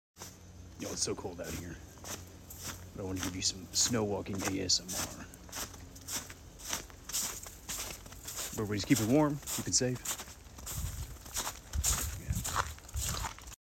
Snow Walking ASMR ❄ sound effects free download